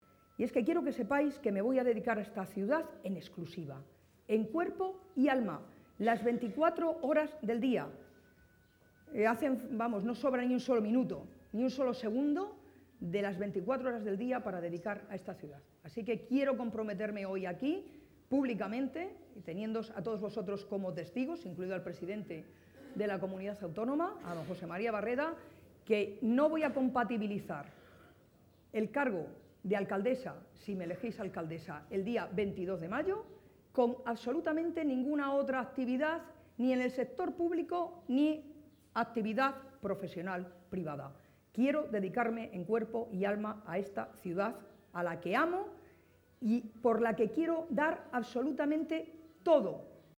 También ha valorado “el entusiasmo, la ilusión y las ideas tan claras” de Magdalena Valerio, a la que ha espetado “¡te veo como alcaldesa!” entre los aplausos de los asistentes, que abarrotaban el Salón de Actos del Conservatorio Provincial de Música.